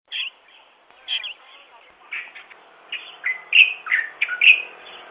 10-2金山白頭翁c+s2.mp3
白頭翁(台灣亞種) Pycnonotus sinensis formosae
錄音地點 新北市 金山區 金山
錄音環境 路旁灌木叢
行為描述 鳴唱及叫
錄音: 廠牌 Denon Portable IC Recorder 型號 DN-F20R 收音: 廠牌 Sennheiser 型號 ME 67